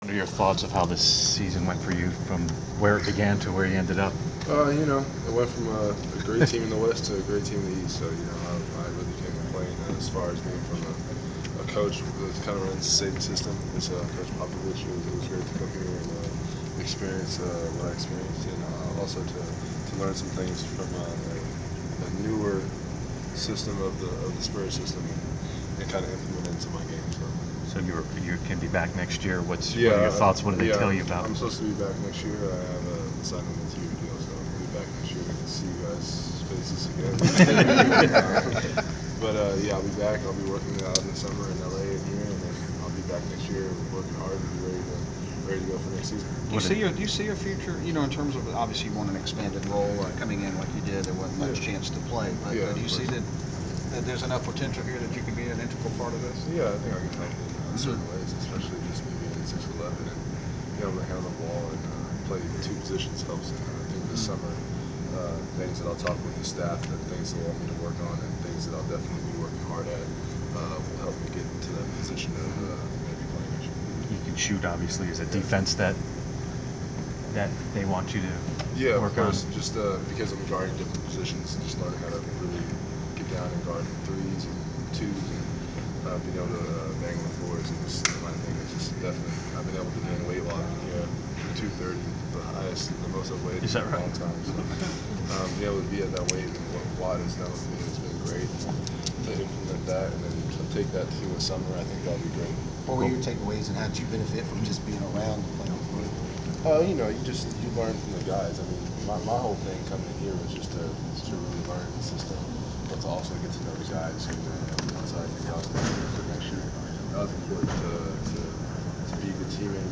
Inside the Inquirer: Media Presser with Atlanta Hawk Austin Daye
The Sports Inquirer attended the media presser of Atlanta Hawks forward Austin Daye following the conclusion of his team’s season. Topics included Daye’s multi-year contract with the Hawks and his offseason training plans.